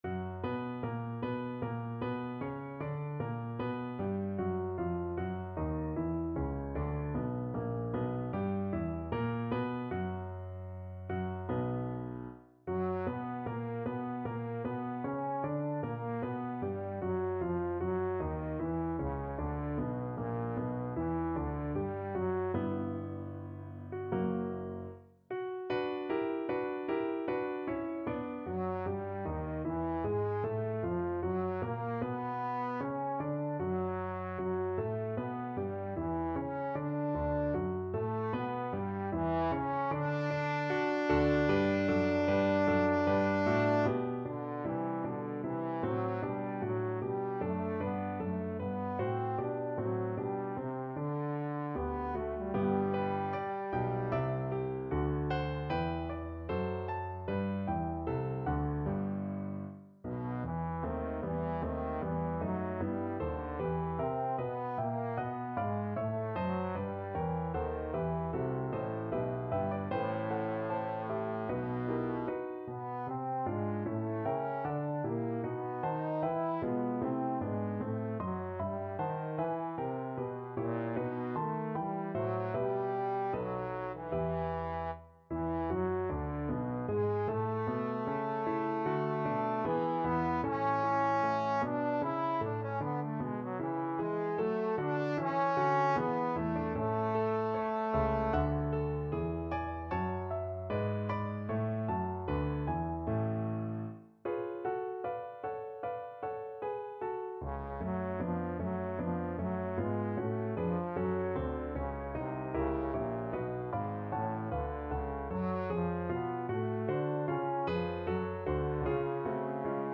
4/4 (View more 4/4 Music)
Larghetto (=76)
Classical (View more Classical Trombone Music)